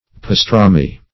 \pas*tra"mi\ (p[aum]s*tr[.a]"m[=e])